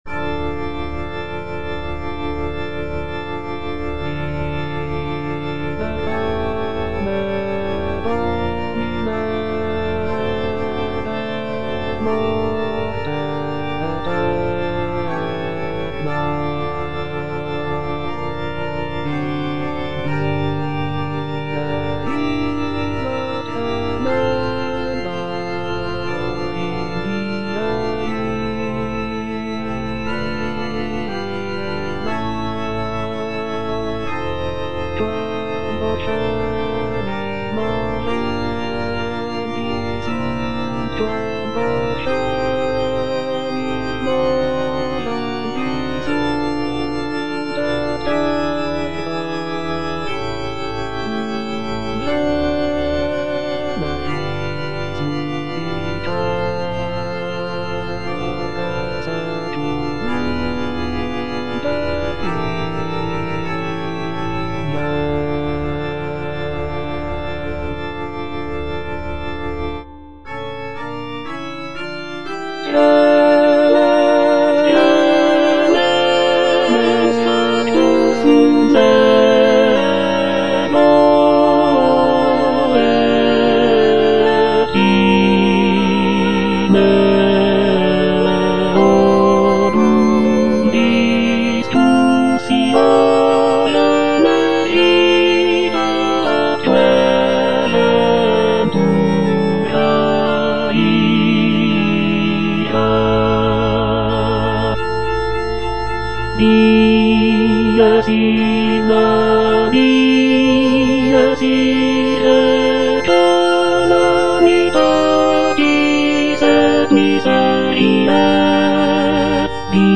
version with a smaller orchestra
(tenor II) (Emphasised voice and other voices) Ads stop